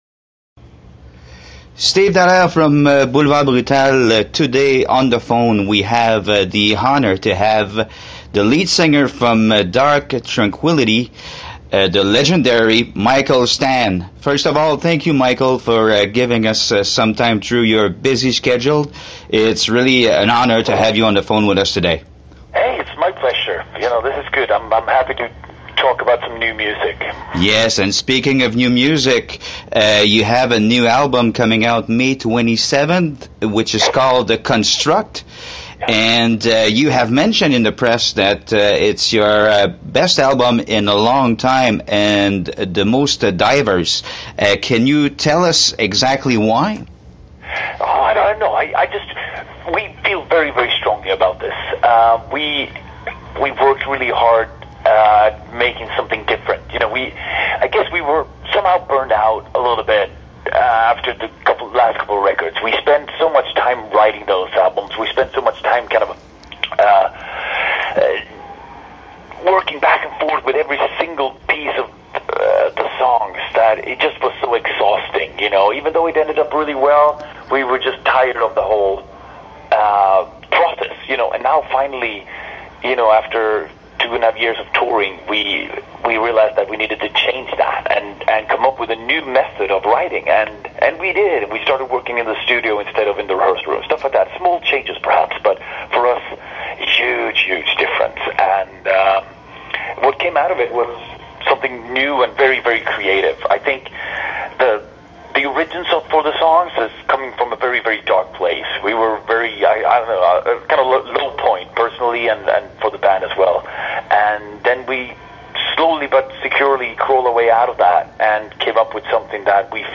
Entrevue avec Mikael Stanne de Dark Tranquillity
Quand le téléphone sonne est que c’est Mikael Stanne, chanteur de Dark Tranquillity à l’autre bout de la ligne, c’est un feeling très spécial.